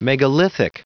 Prononciation du mot megalithic en anglais (fichier audio)
Prononciation du mot : megalithic